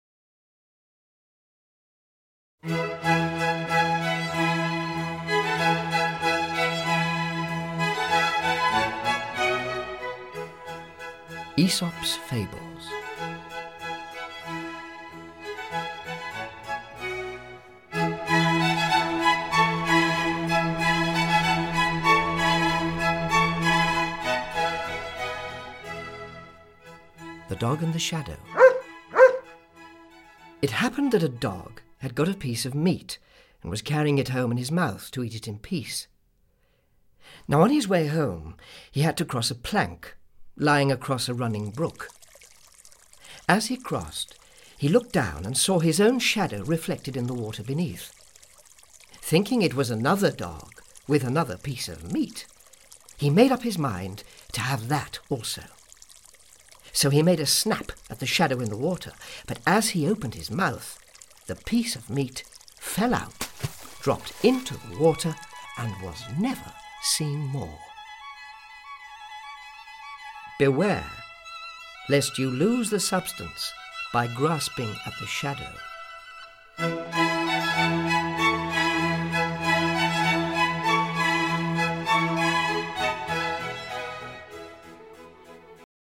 Ukázka z knihy
The stories are told with the enduring magic of a master storyteller, and set against the bright sounds of Vivaldi’s Four Seasons and other concertos.
• InterpretAnton Lesser